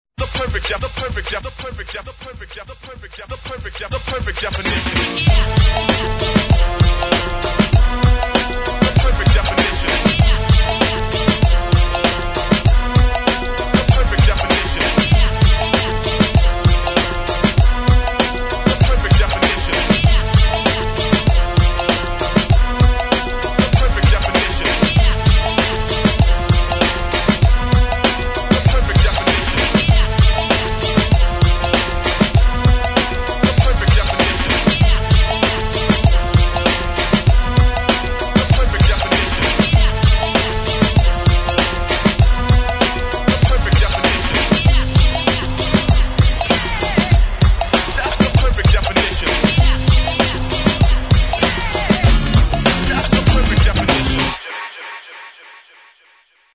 [ HIP HOP ]